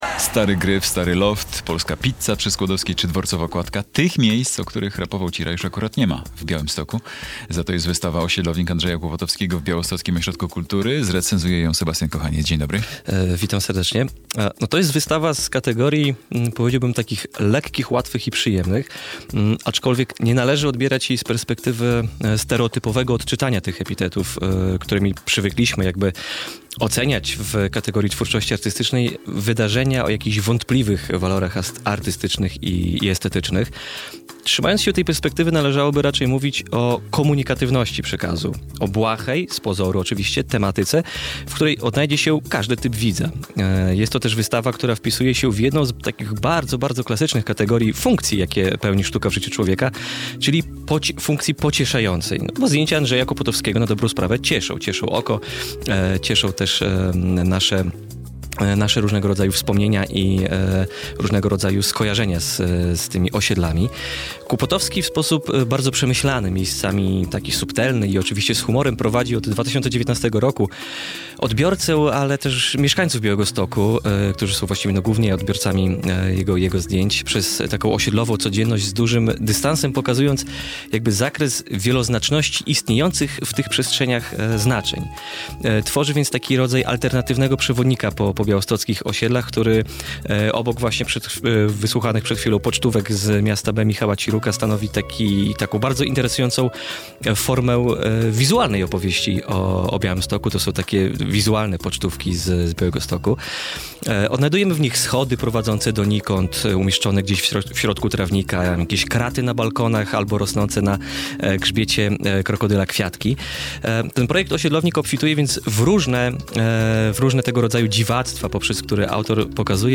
dźwięk do pobrania"Osiedlownik" - recenzja